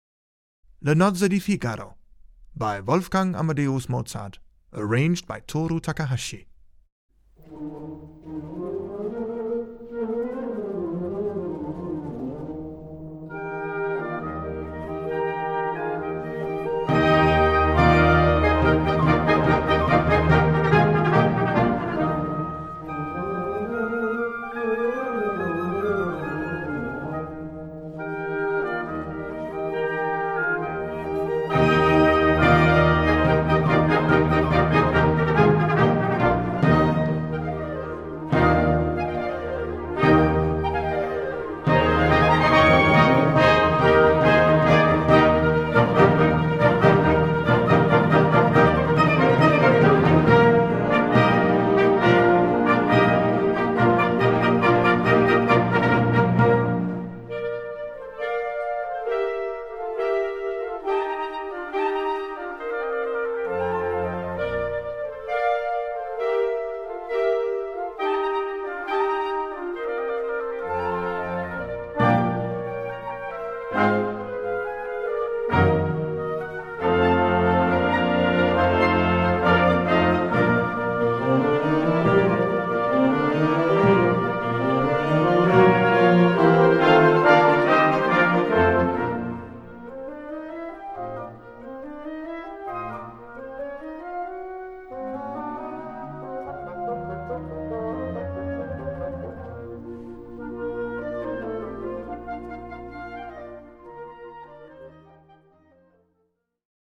E-flat Major（原調： D Major）
参考音源